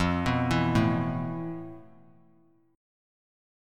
Listen to FM7b5 strummed